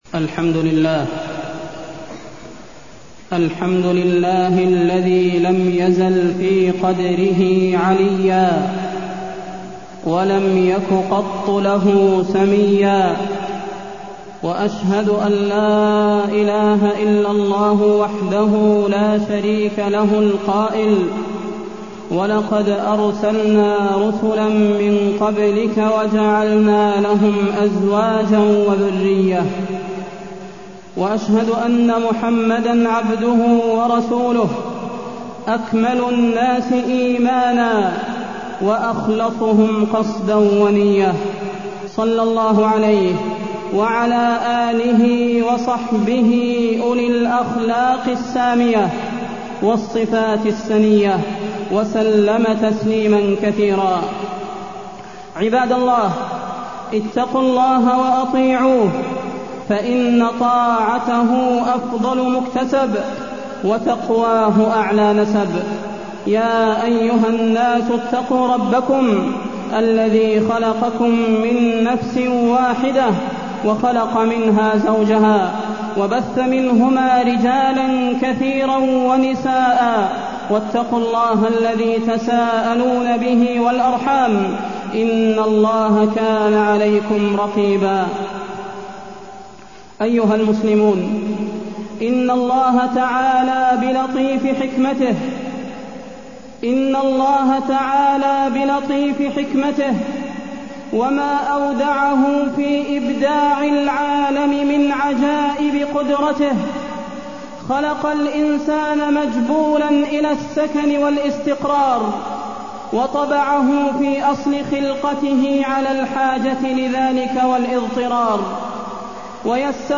تاريخ النشر ١٧ جمادى الآخرة ١٤٢١ هـ المكان: المسجد النبوي الشيخ: فضيلة الشيخ د. صلاح بن محمد البدير فضيلة الشيخ د. صلاح بن محمد البدير نصائح وتوجيهات للزوجين